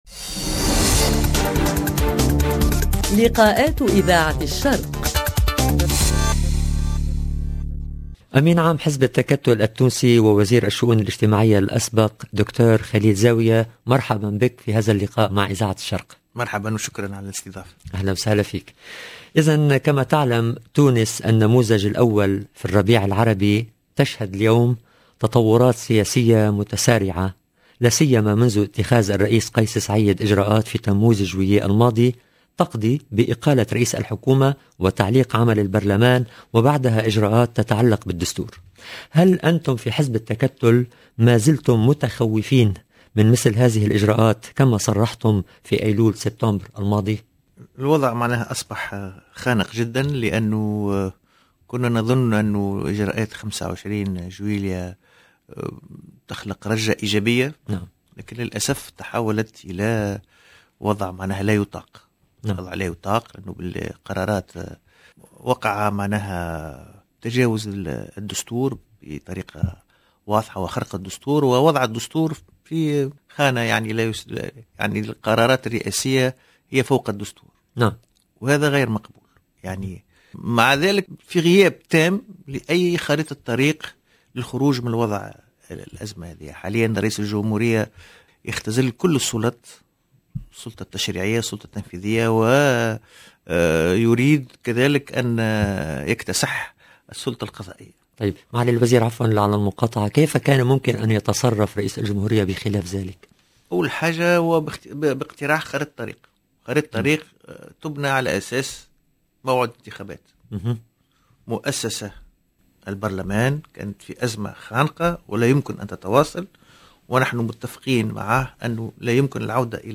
LE PRÉSIDENT DU PARTI ETTAKATOL EN TUNISIE, KHALIL ZAOUIA, INVITÉ DE LIKAAT
Emission diffusée le mercredi 10 novembre 2021